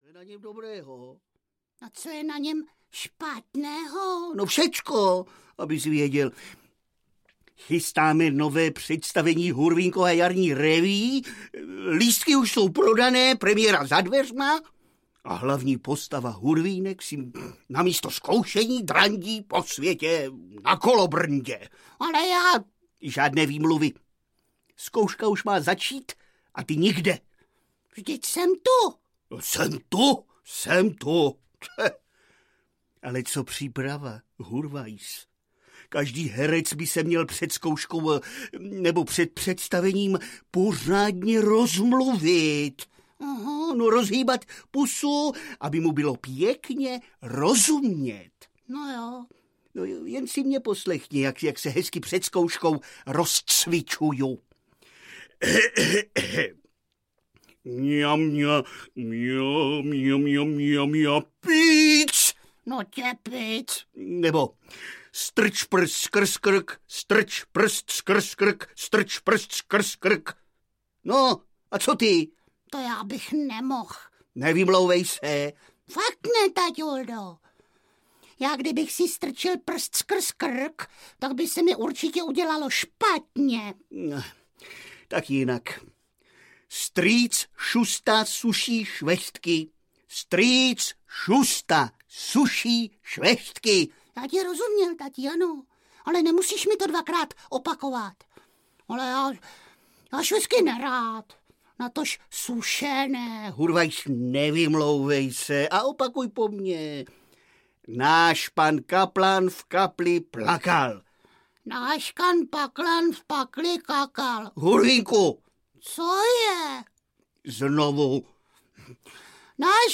Jak si Hurvínek Máničku našel audiokniha
Ukázka z knihy
Hra, uvedená před lety na jevišti Divadla S+H, je plná písniček a typického hurvínkovského humoru.